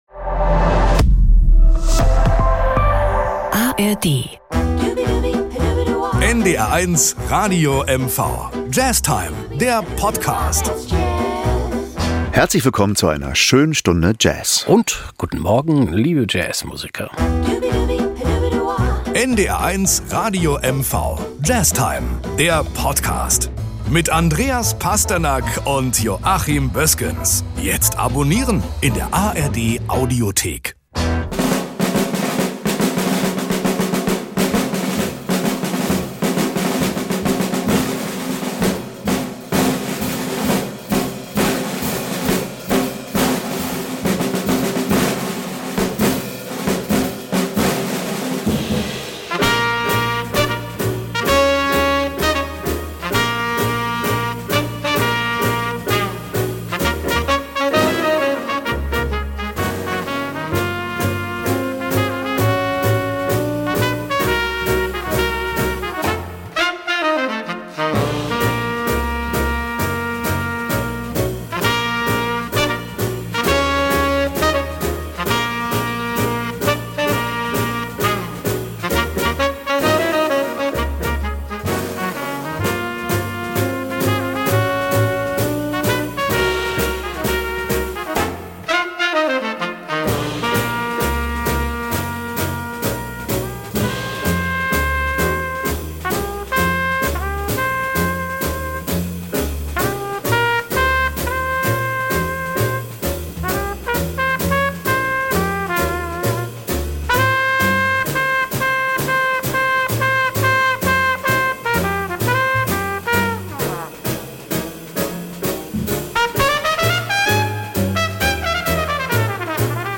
ein Standard